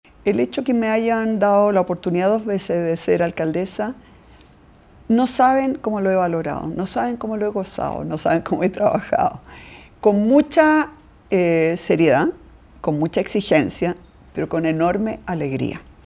En conversación con La Radio, la actual jefa comunal destacó el cargo que dejará este viernes como el “mayor honor” que ha tenido en su vida.
evelyn-matthei-1.mp3